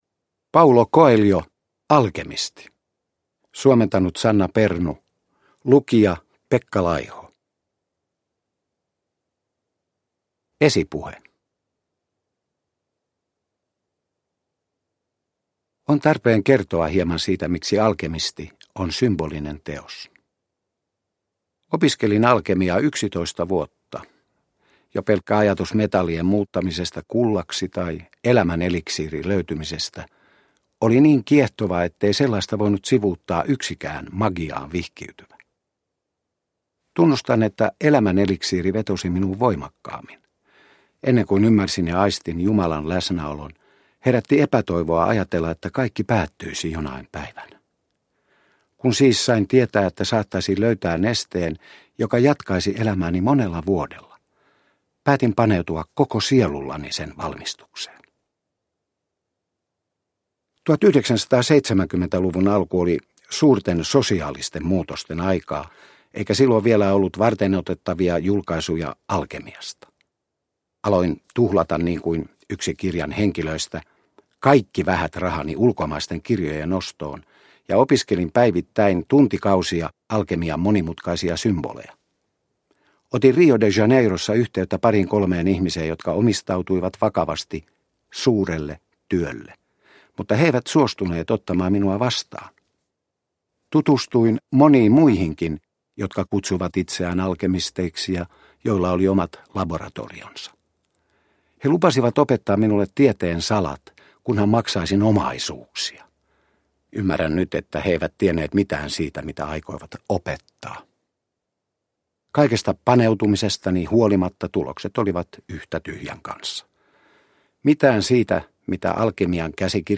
Alkemisti – Ljudbok – Laddas ner